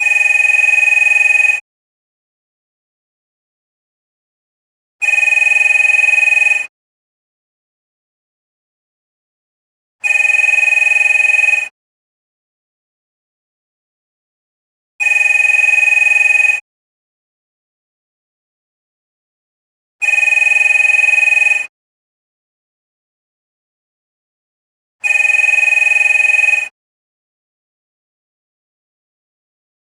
6 rings from the last telephone box still in operation in metropolitan France. It is in Murbach (68). Easy to loop.
Listen : Telephone booth ringtone #2 (30 s)